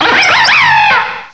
cry_not_swoobat.aif